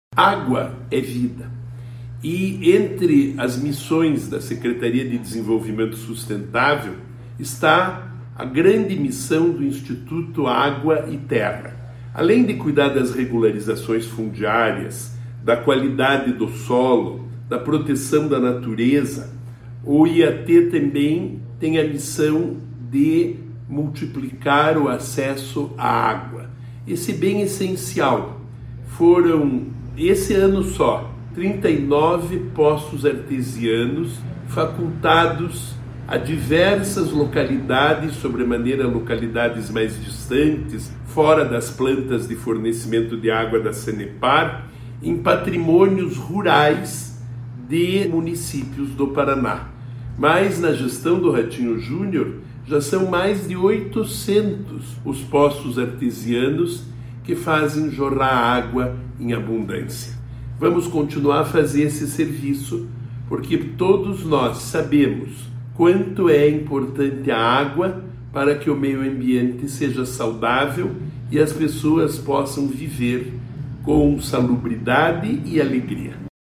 Sonora do secretário do Desenvolvimento Sustentável, Rafael Greca, sobre o Programa Água no Campo